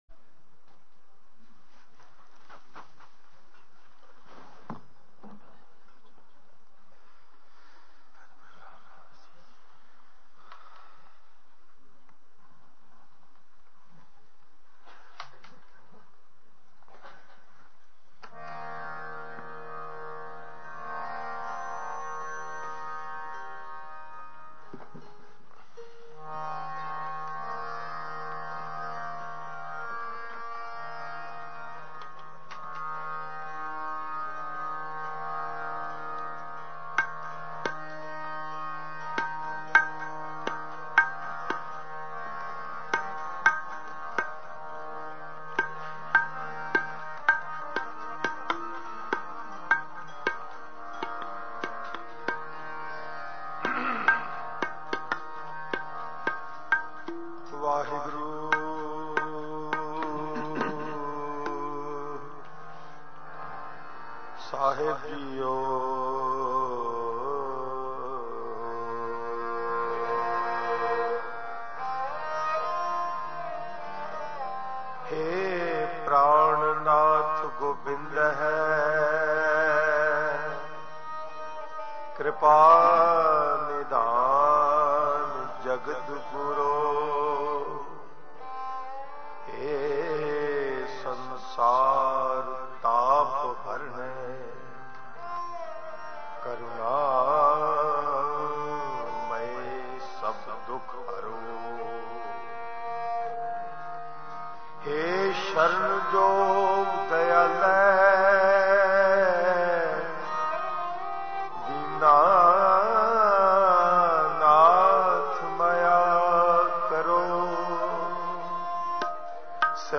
A ballad in Raag Asa, recited in congregations every morning.